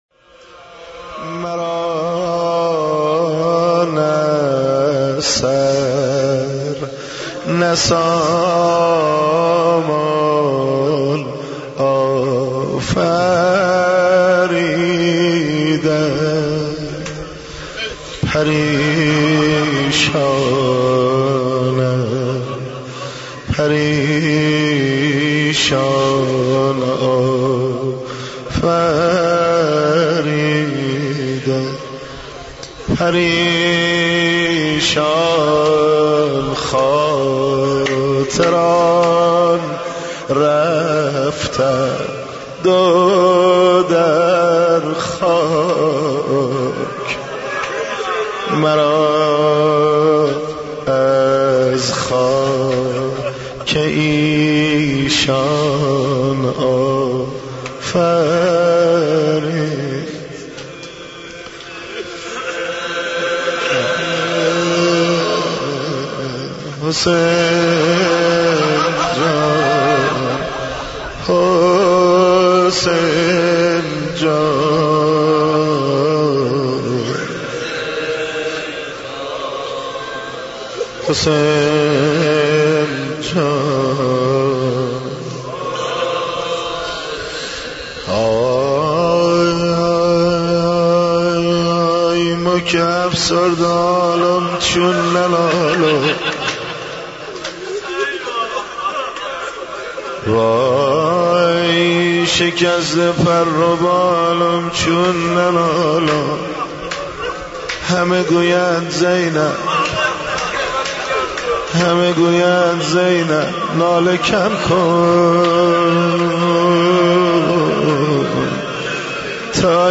مناجات با امام زمان عجل الله